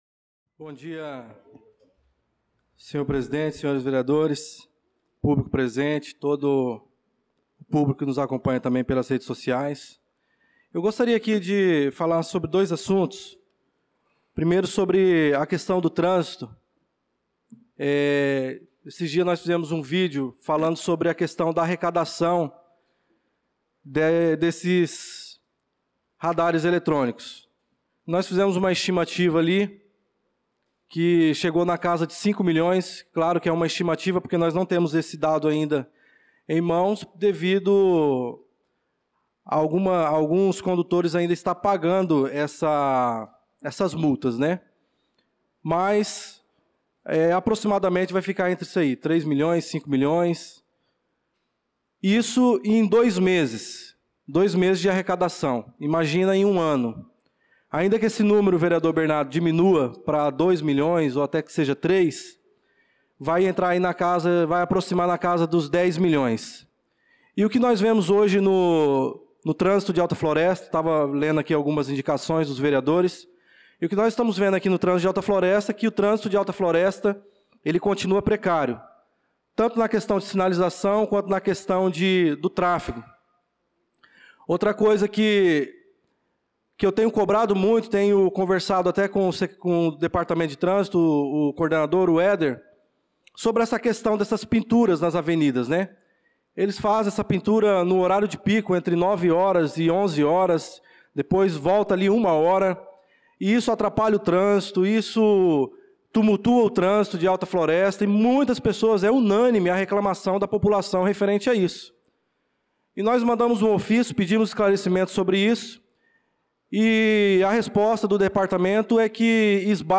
Pronunciamento do vereador Darlan Carvalho na Sessão Ordinária do dia 18/08/2025.